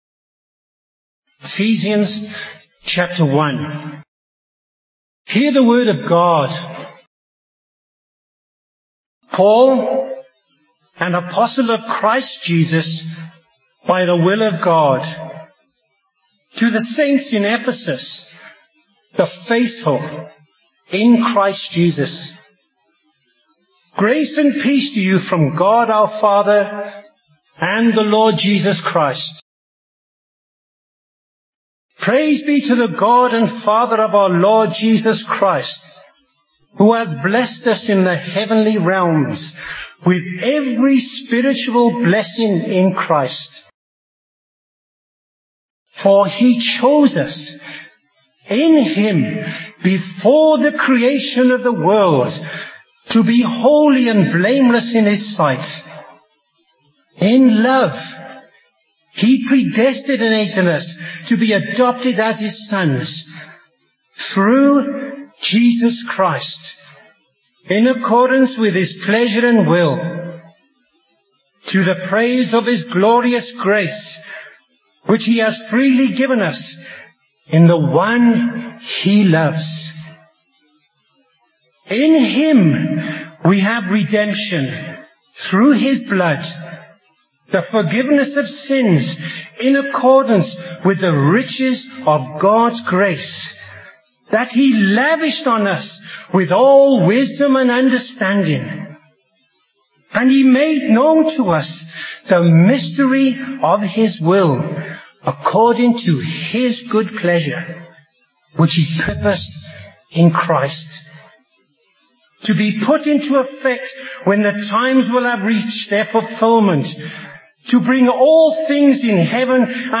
Bible Text: Ephesians 1:1-10, Psalm 16:1-11 | Preacher: Bishop Warwick Cole-Edwards | Series: Ephesians